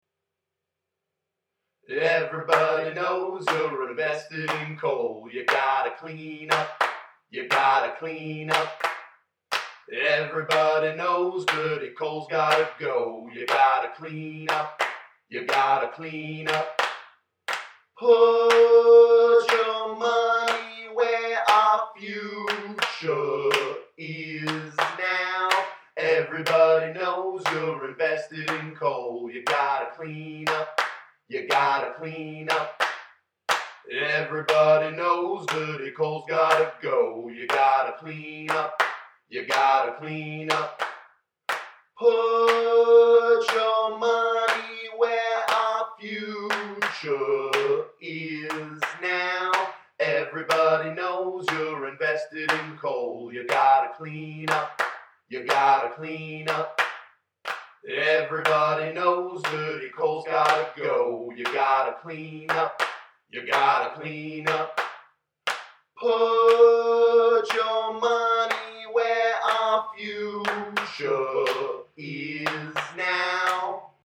The icing on the cake was walking out to a larger group of justice allies protesting outside,